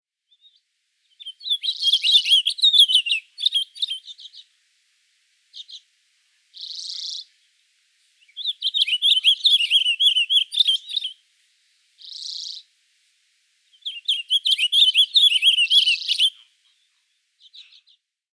ギンザンマシコ
【分類】 スズメ目 アトリ科 ギンザンマシコ属 ギンザンマシコ 【分布】北海道 【生息環境】ハイマツ林で繁殖 冬期にはより低地の針葉樹林に生息 【全長】22cm 【主な食べ物】植物の種子 【鳴き声】さえずり 【聞きなし】「ピチュル チュルリ チュルリ チルル」